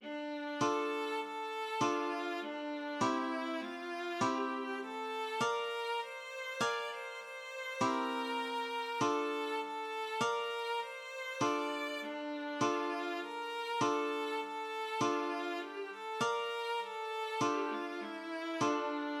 / Komponist: (traditional)